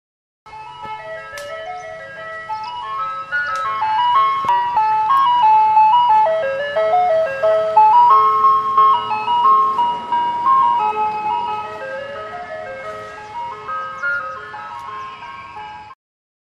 جلوه های صوتی
دانلود صدای ماشین بستنی فروشی 2 از ساعد نیوز با لینک مستقیم و کیفیت بالا